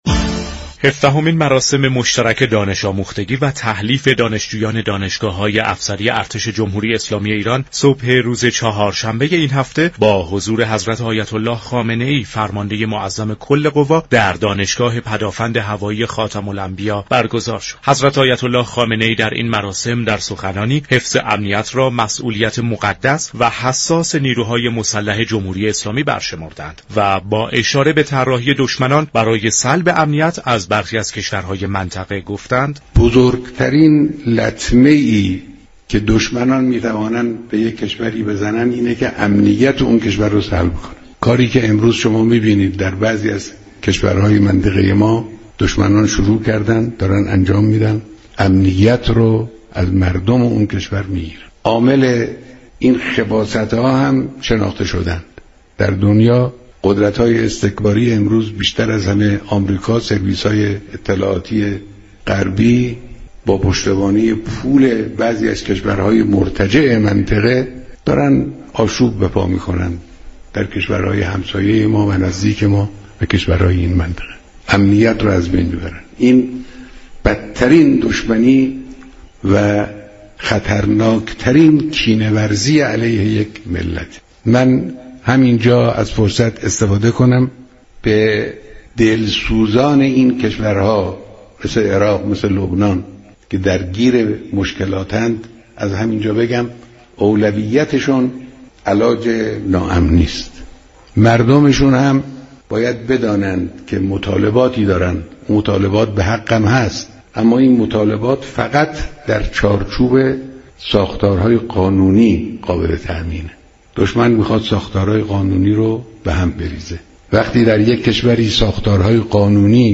كارشناس مسائل سیاسی